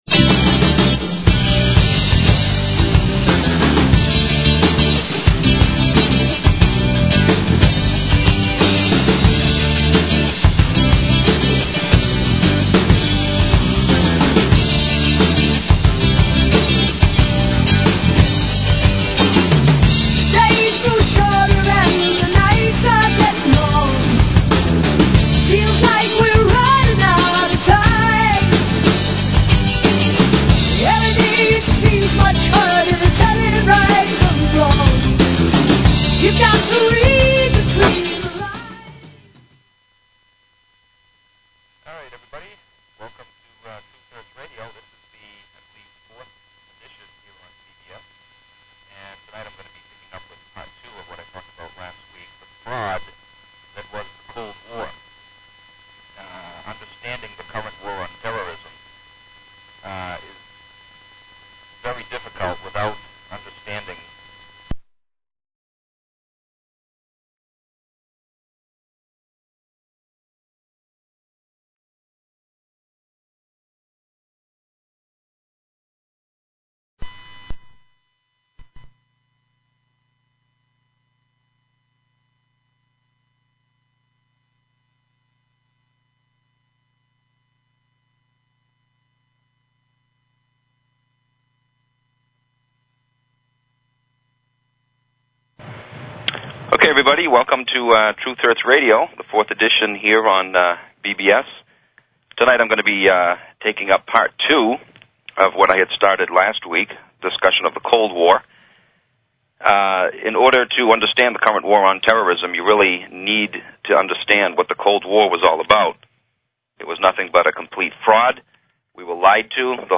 Talk Show Episode, Audio Podcast, Truth_Hertz_Radio and Courtesy of BBS Radio on , show guests , about , categorized as